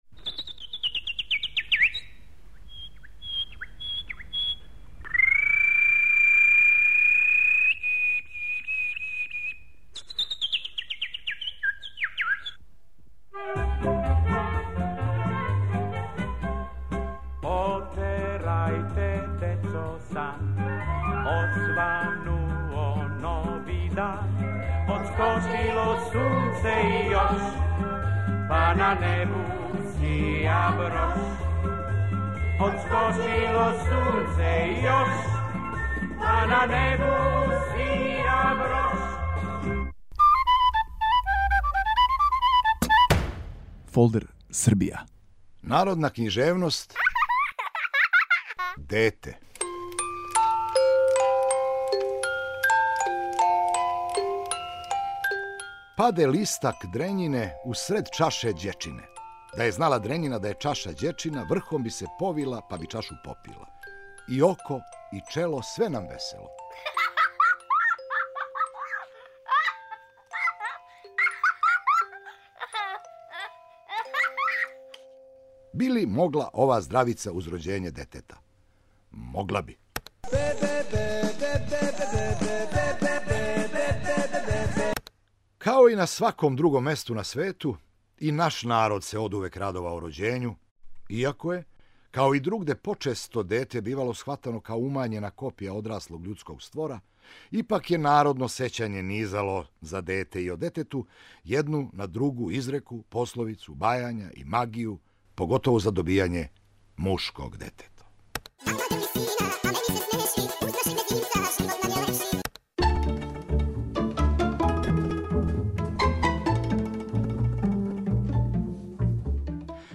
Ваш водич кроз Србију: Бранимир Брстина